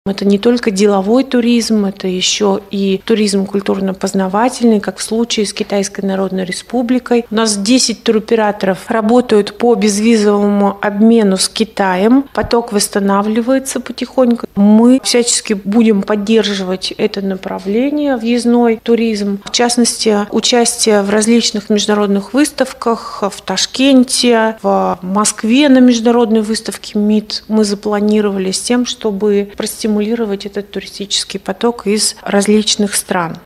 Увеличивается турпоток из стран, с которыми у Среднего Урала есть прямое авиасообщение, — рассказала директор регионального департамента по развитию туризма Эльмира Туканова на пресс-конференции «ТАСС-Урал».